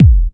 TR 909 Kick 03.wav